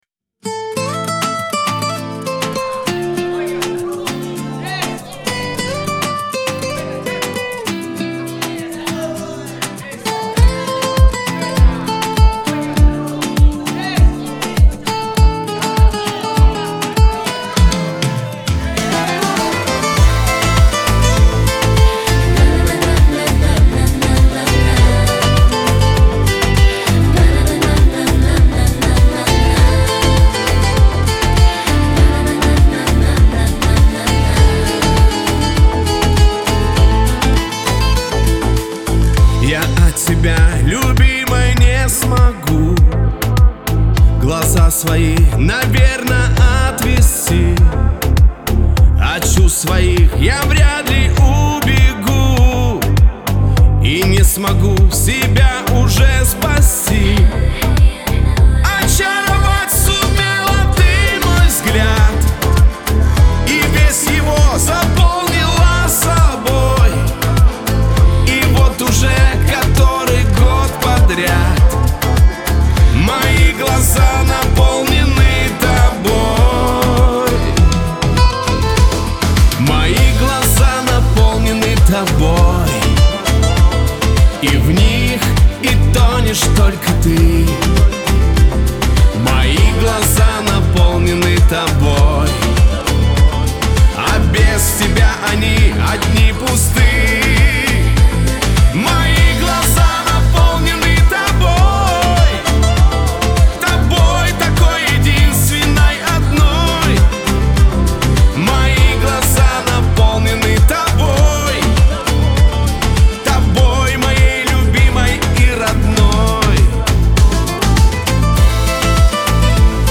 Лирика , Шансон